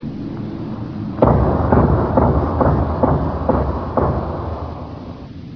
(Ghost Knocking....ooooohhhh ssssscary...LOL)
ghostknock.ram